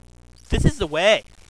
In "real" life -- if you choose to call it real -- my voice is a sheep's bleat.
If you are a masochist, feel free to download these samples of my voice, simulating stupid stuff.